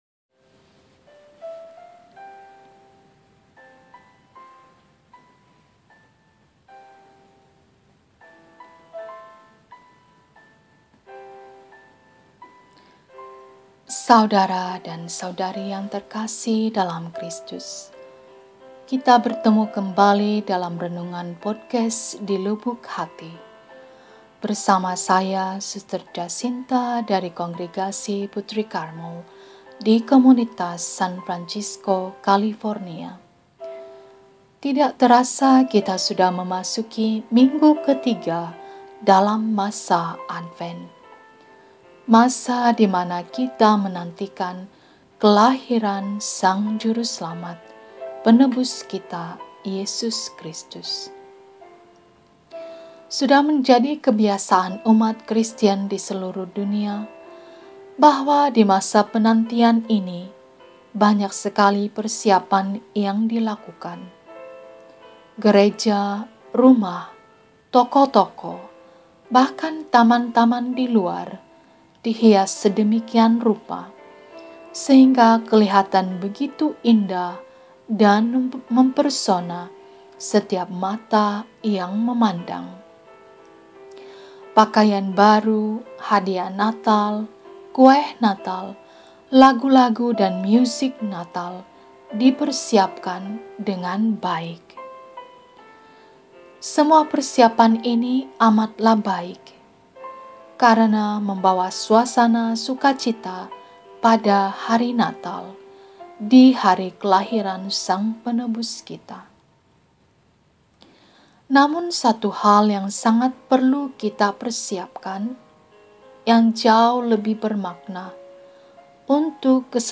Renungan Natal